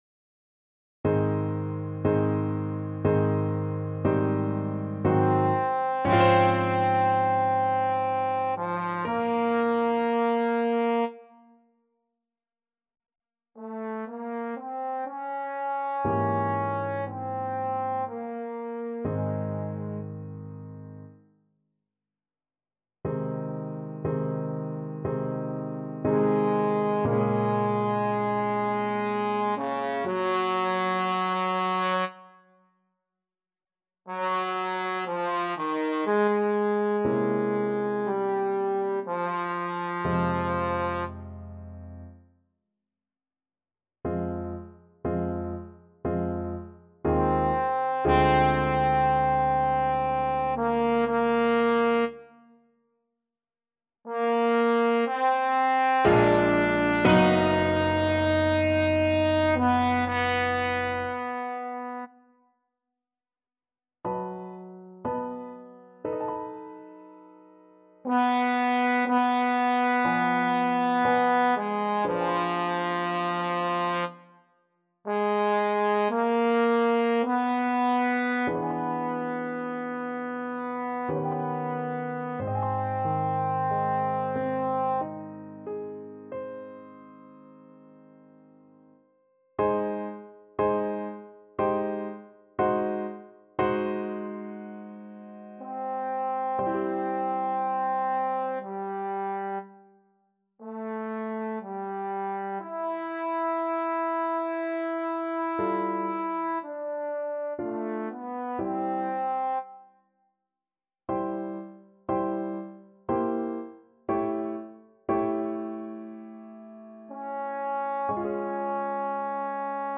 Trombone version
~ = 60 Langsam, leidenschaftlich
3/4 (View more 3/4 Music)
Classical (View more Classical Trombone Music)